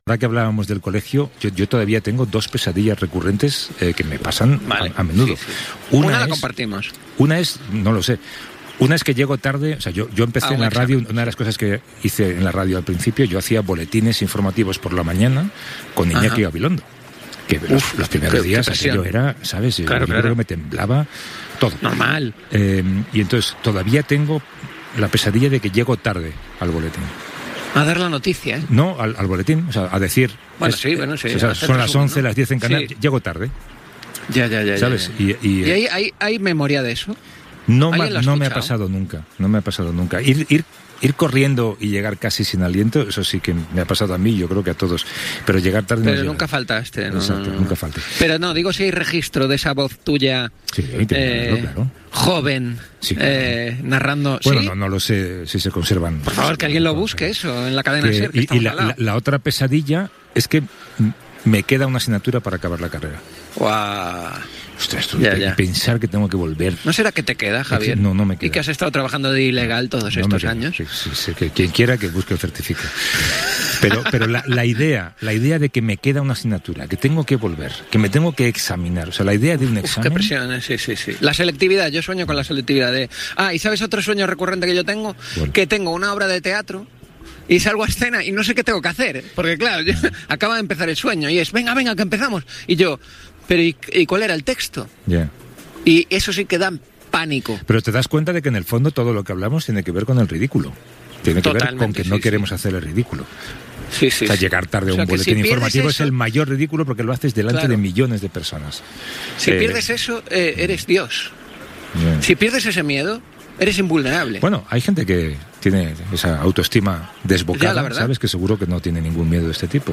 Entreteniment
Presentador/a
Pino, Javier del